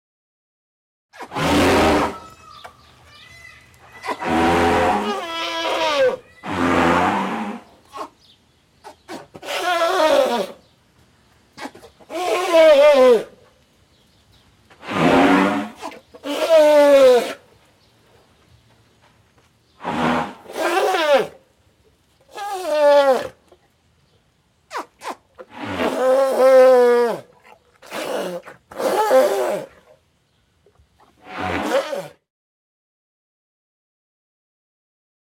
Elephant Trumpet. Baby Trumpets. Medium Perspective.